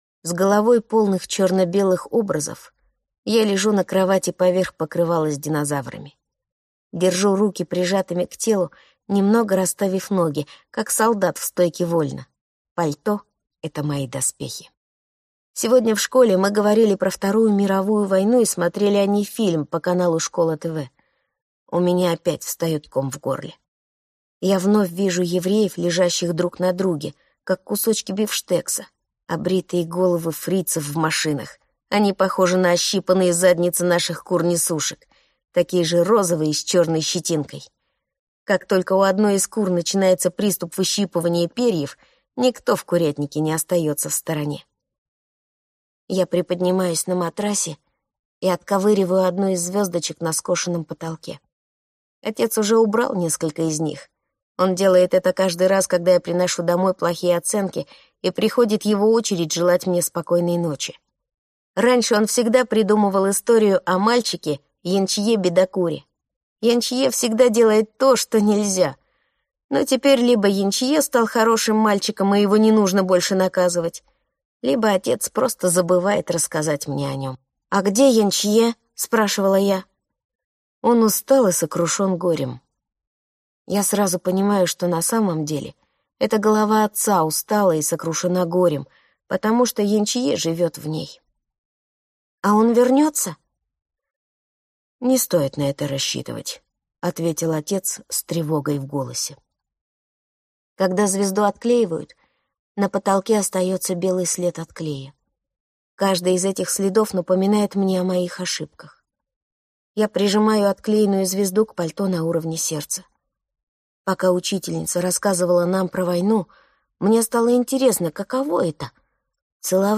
Аудиокнига Неловкий вечер | Библиотека аудиокниг